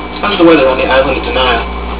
These wavs were taken directly from the ABC broadcasts.